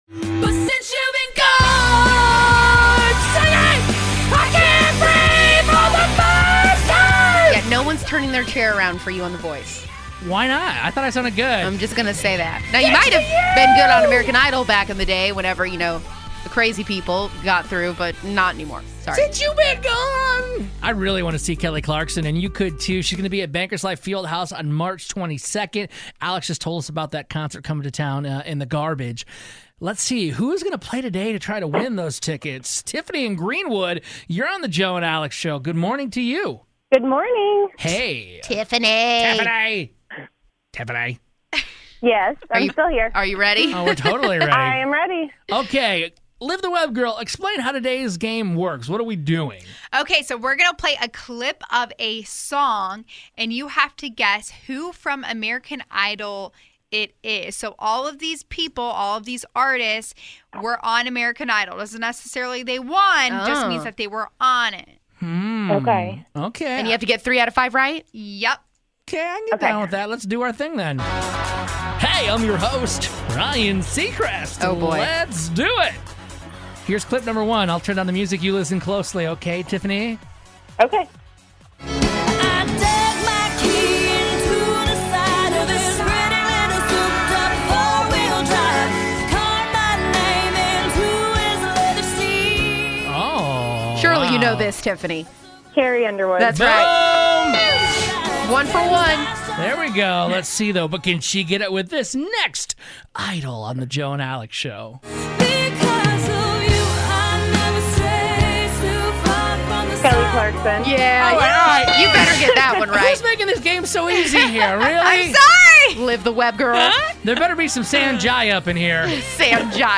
We have a caller guess which contestant of American Idol is singing the song we play in order to win tickets to go see Kelly Clarkson